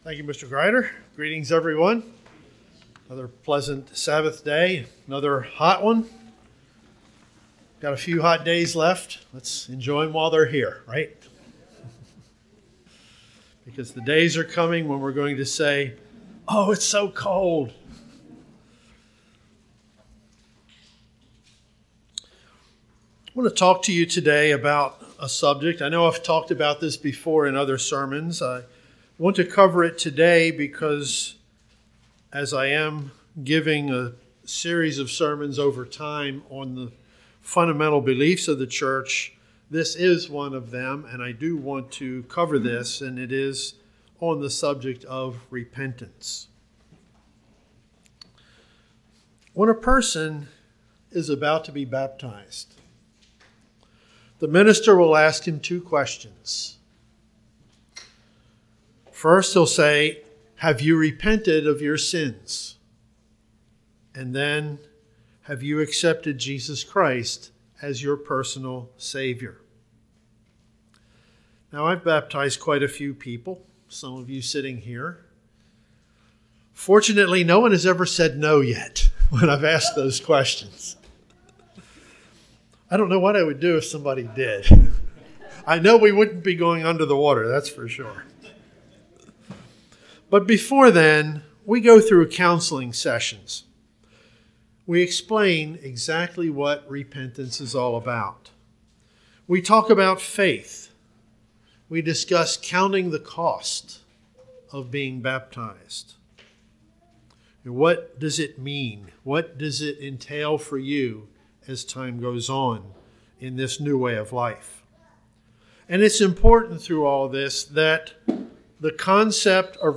Given in Columbia, MD